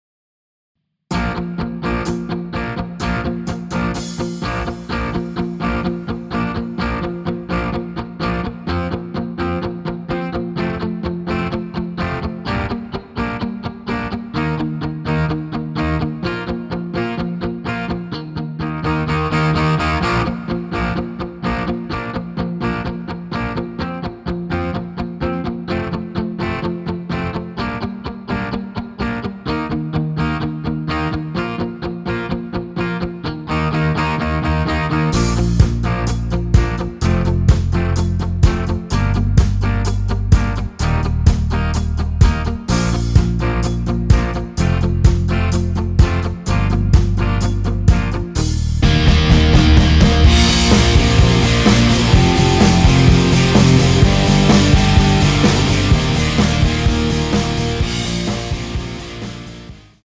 전주없이 노래 시작 하는 곡이라 전주 만들어 놓았습니다.
(하이 햇 소리 끝나고 노래 시작 하시면 됩니다.)(멜로디 MR 미리듣기 확인)
원키에서(-1)내린 MR입니다.
Bb
앞부분30초, 뒷부분30초씩 편집해서 올려 드리고 있습니다.